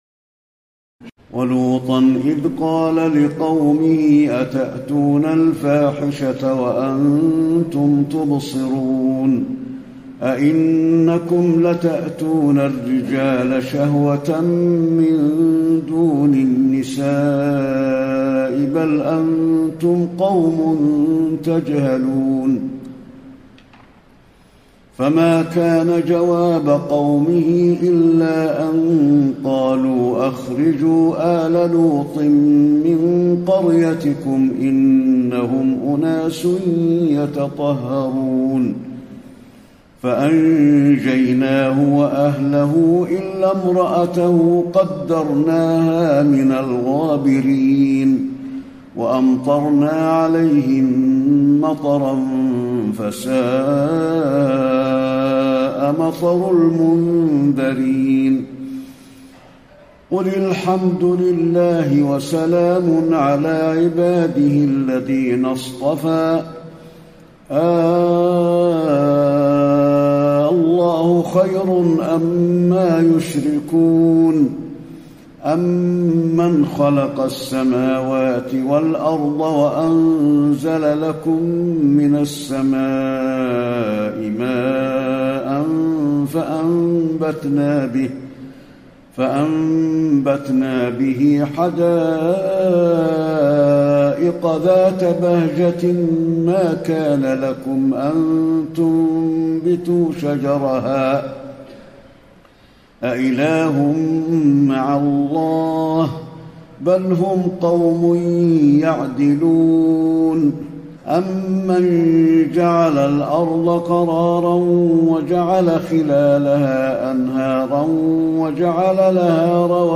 تراويح الليلة التاسعة عشر رمضان 1434هـ من سورتي النمل(54-93) و القصص(1-50) Taraweeh 19 st night Ramadan 1434H from Surah An-Naml and Al-Qasas > تراويح الحرم النبوي عام 1434 🕌 > التراويح - تلاوات الحرمين